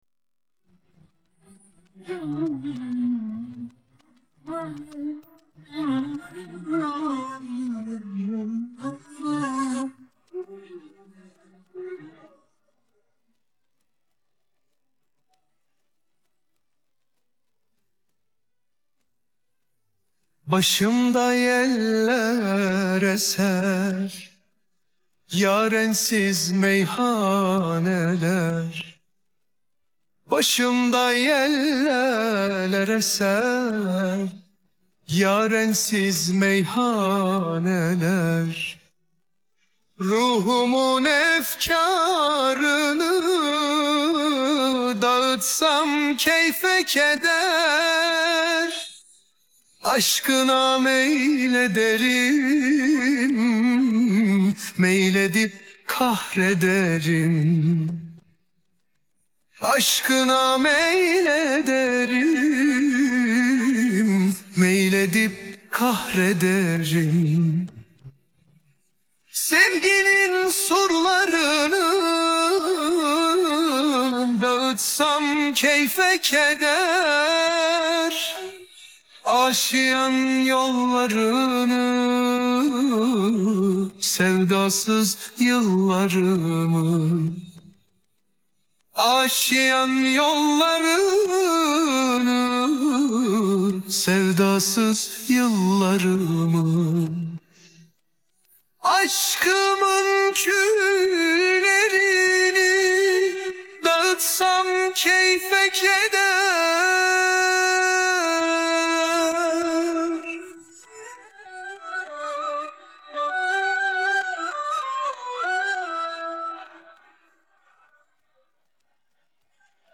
Vocal Part